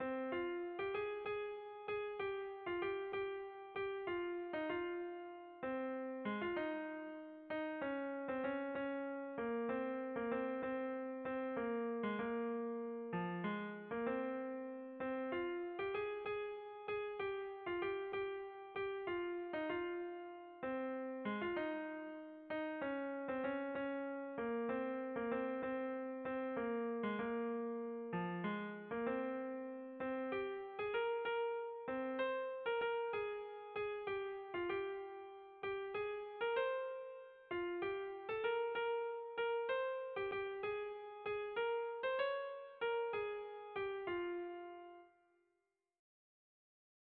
Kontakizunezkoa
Hamabiko handia (hg) / Sei puntuko handia (ip)
A-B-A-B-D-